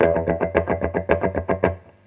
RIFF5.wav